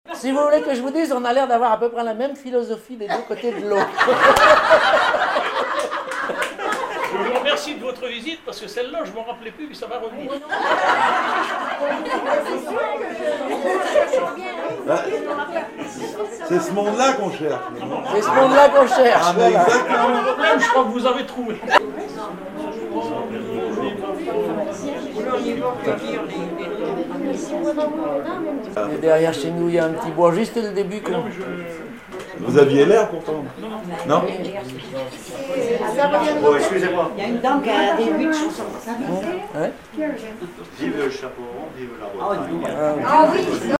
Informateur(s) Club d'anciens de Saint-Pierre association
Catégorie Témoignage